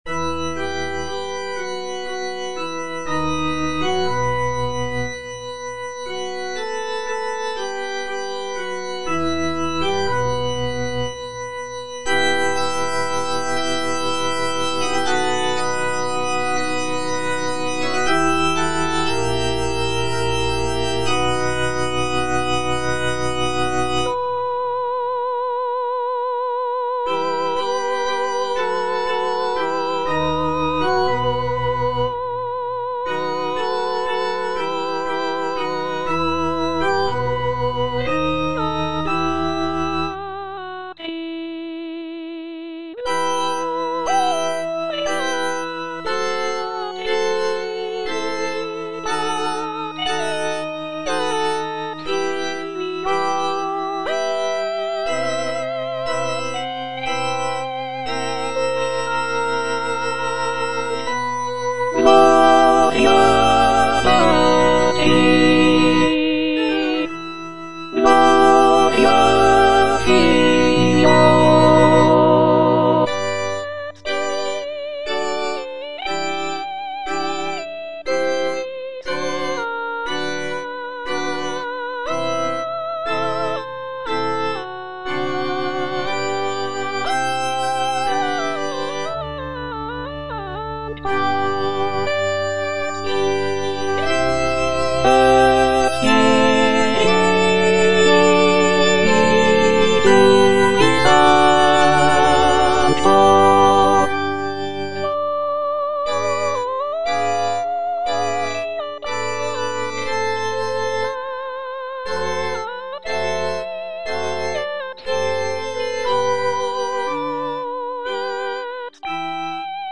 Gloria - Alto (Emphasised voice and other voices) Ads stop